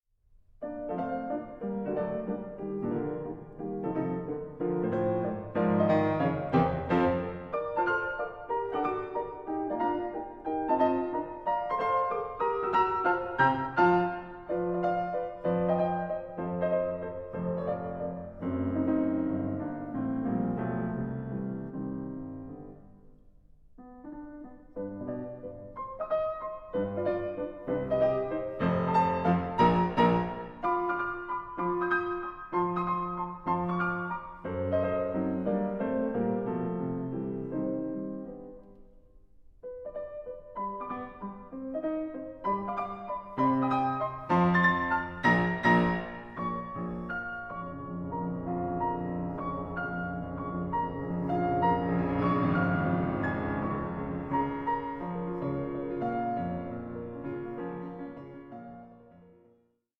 Piano Sonata No. 29 in B-flat Major, Op. 106